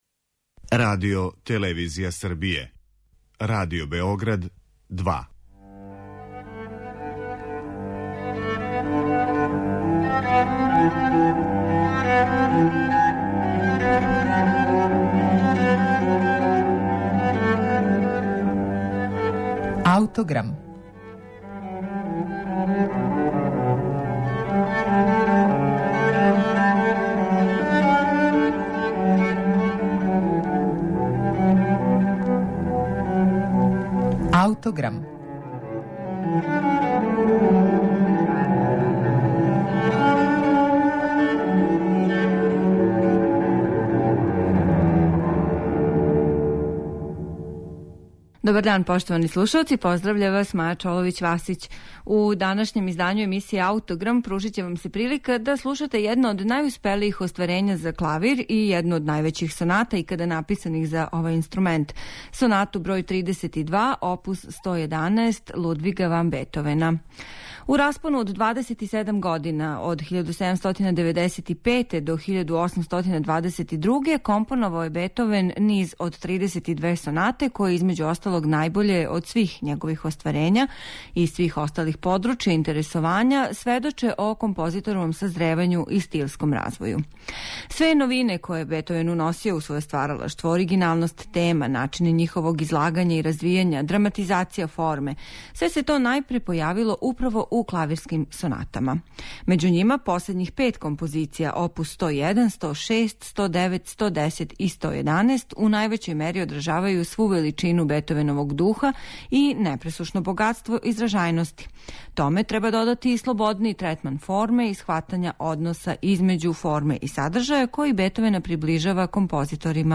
Соната оп. 111 бр. 32 у це-молу настала је у периоду од 1821. до 1822. године. Обликована је као диптих од два става различитих (контрастних) карактера којима Бетовен истиче себи својствен дуализам: драматични молски први став побеђује лирски Це-дур - Аријета са варијацијама у II ставу.